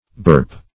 burp \burp\ v. i.